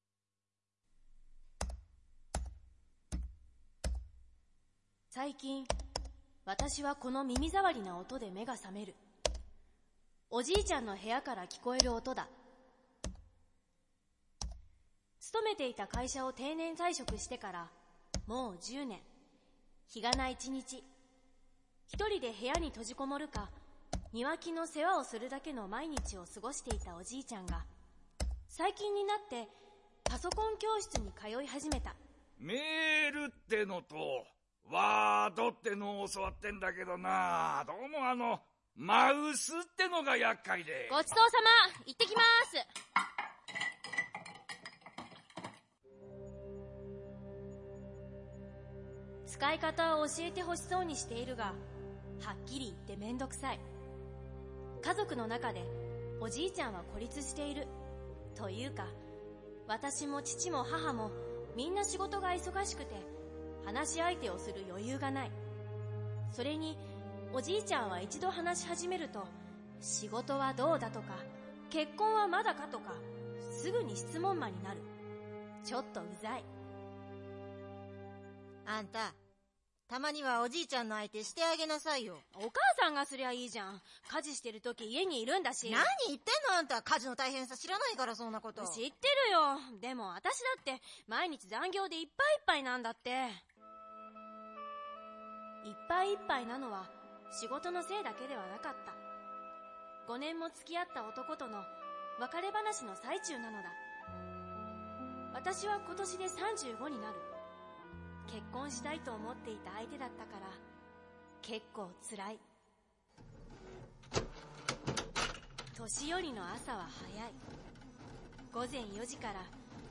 BSNラジオドラマ
制作協力：劇団あんかーわーくす